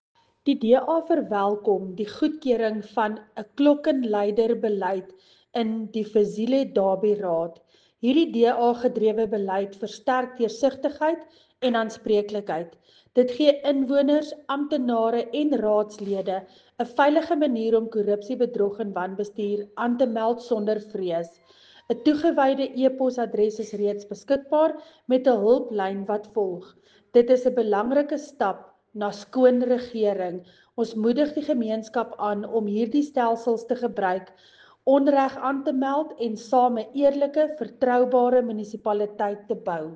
Afrikaans soundbite by Cllr Linda Louwrens.